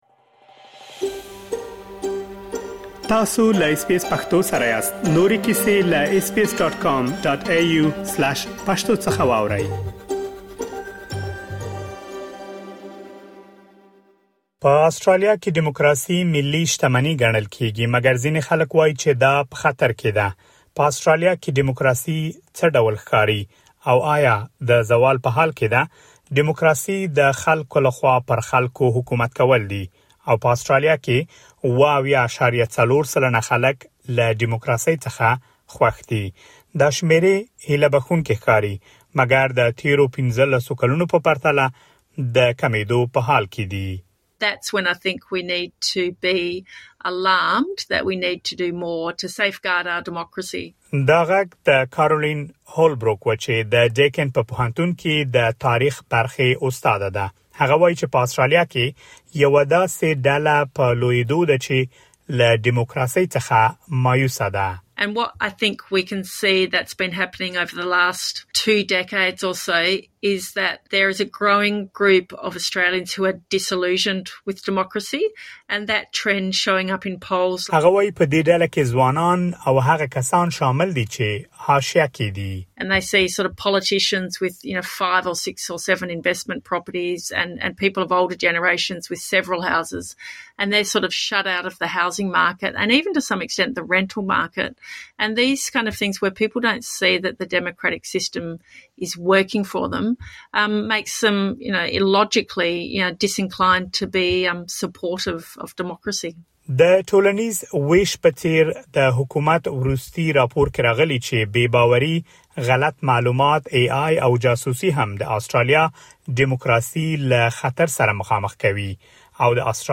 مګر ځینې خلک وايي چې آسټرالیا کې ډیموکراسي په خطر کې ده. په دې اړه مهم معلومات دلته په رپوټ کې اورېدلی شئ.